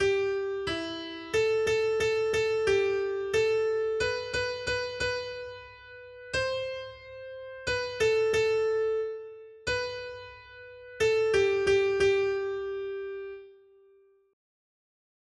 Noty Štítky, zpěvníky ol664.pdf responsoriální žalm Žaltář (Olejník) 664 Skrýt akordy R: Já usmrcuji a já oživuji. 1.